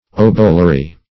Meaning of obolary. obolary synonyms, pronunciation, spelling and more from Free Dictionary.
Search Result for " obolary" : The Collaborative International Dictionary of English v.0.48: Obolary \Ob"o*la*ry\, a. [See Obolus .]